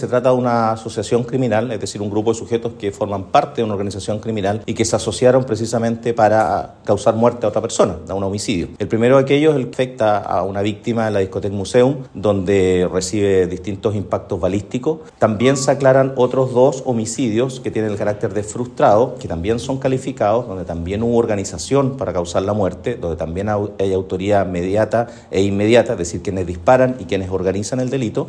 El fiscal Michelangelo Bianchi informó que los imputados se asociaron para causar la muerte a otra persona. A la banda se le atribuyen otros dos homicidios frustrados y calificados.